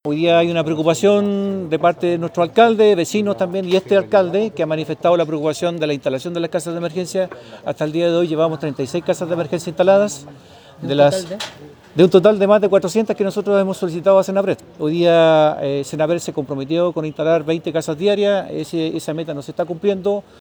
Por su parte, el alcalde de Tomé, Ítalo Cáceres, también se quejó por la lentitud de la llegada de las viviendas de emergencia, asegurando que en su comuna se requieren más de 400 y que poco más de 30 ya se encuentran construidas, algunas de ellas con observaciones.